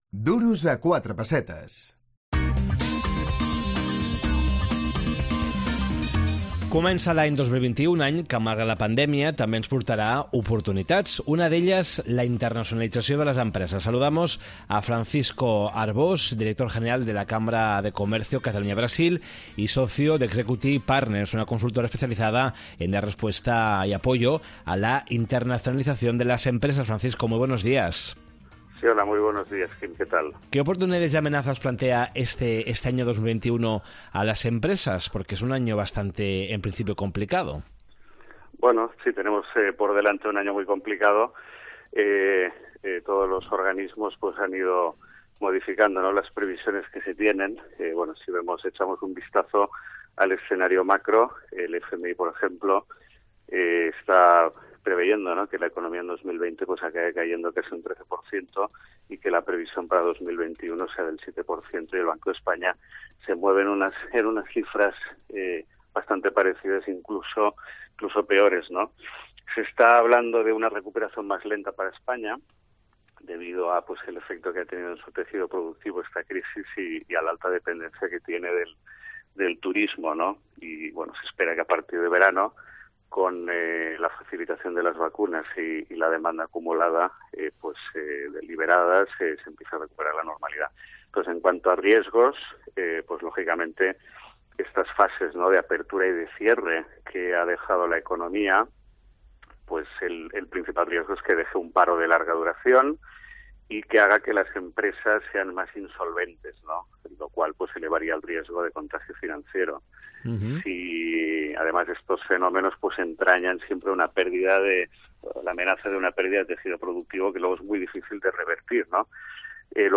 Duros a quatre pessetes, el programa d’economia de COPE Catalunya i Andorra.